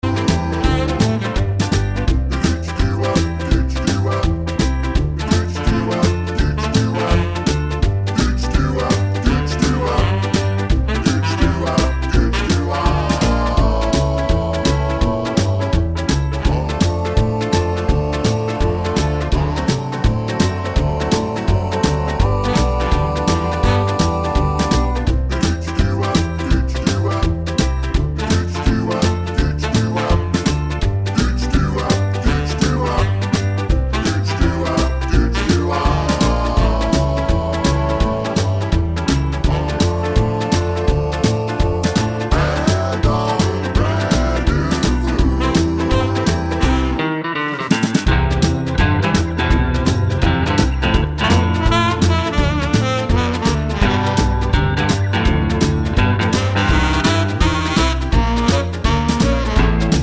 Down 2 Semitones Soul / Motown 2:31 Buy £1.50